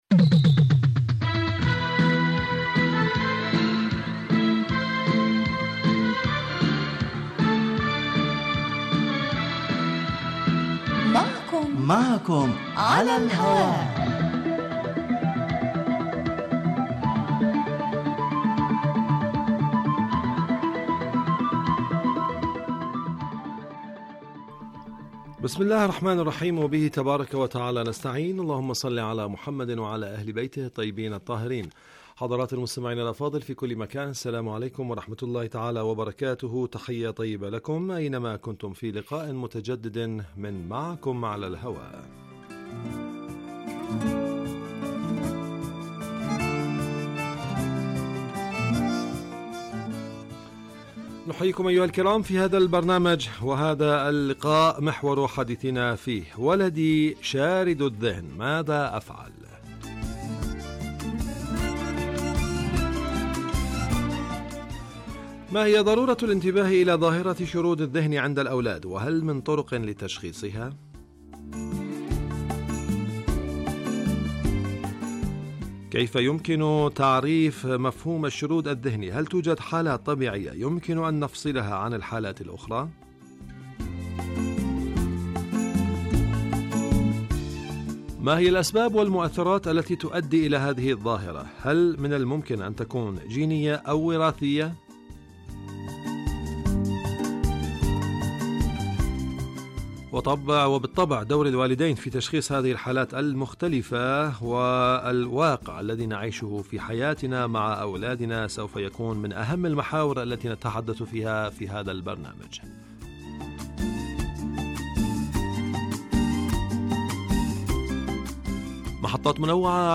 من البرامج المعنية بتحليل القضايا الاجتماعية في دنيا الإسلام و العرب و من أنجحها الذي يلحظ الكثير من سياسات القسم الاجتماعي بصورة مباشرة علي الهواء وعبر الاستفادة من رؤي الخبراء بشان مواضيع تخص هاجس المستمعين.